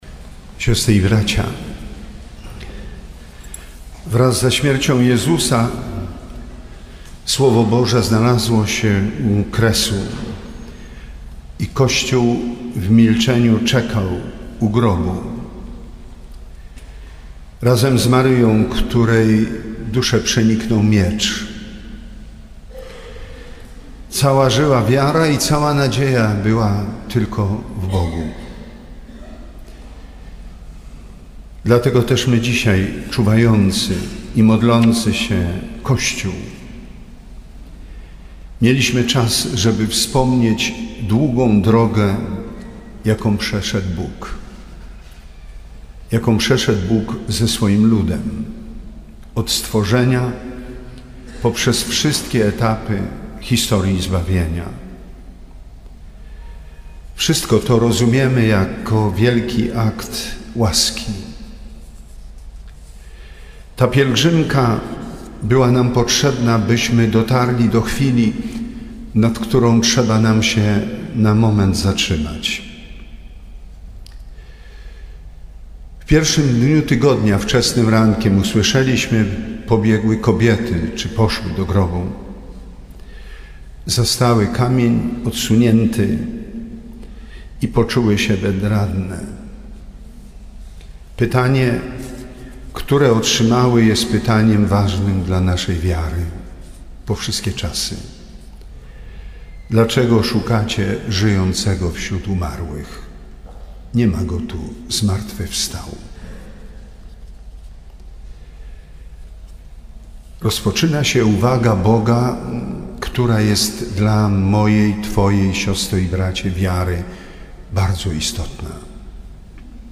Bp Edward Dajczak przewodniczył Liturgii Wigilii Paschalnej w koszalińskiej katedrze.